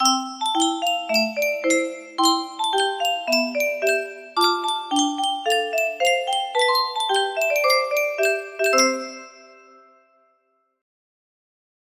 Just another ringtone music box melody